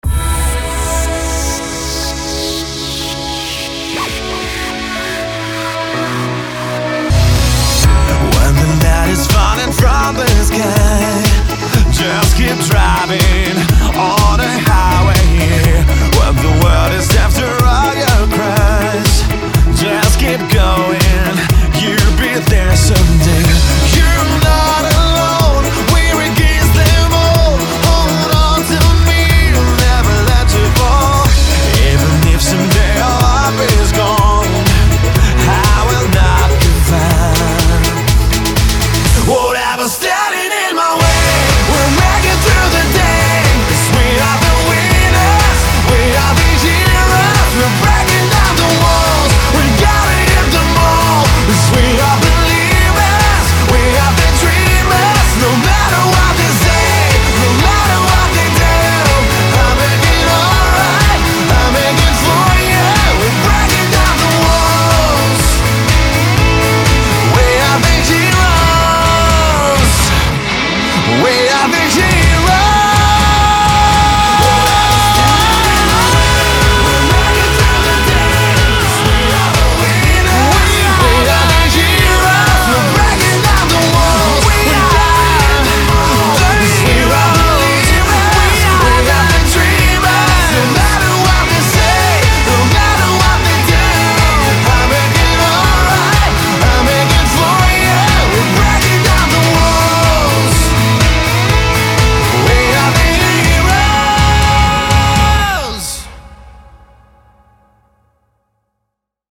BPM123
Audio QualityMusic Cut